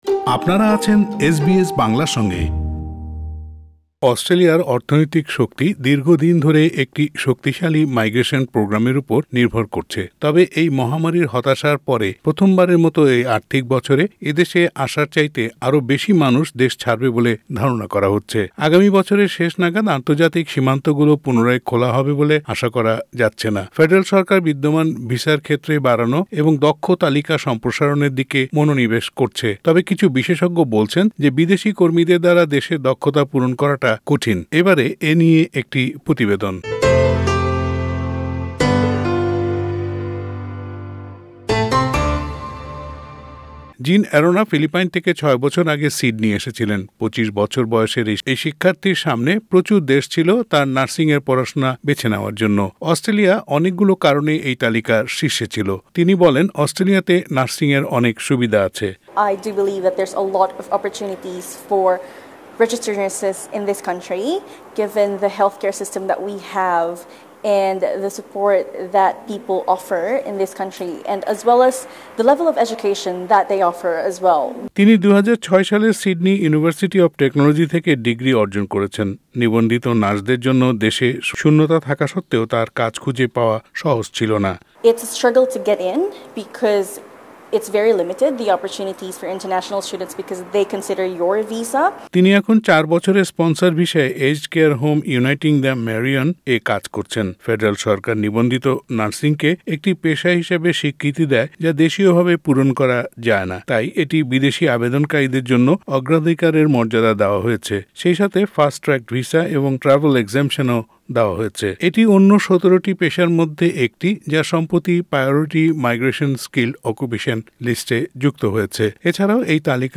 তবে কিছু বিশেষজ্ঞ বলেছেন যে বিদেশী কর্মীদের দ্বারা দেশের দক্ষতা পূরণ করাটা কঠিন। প্রতিবেদনটি শুনতে উপরের অডিও লিংকটিতে ক্লিক করুন।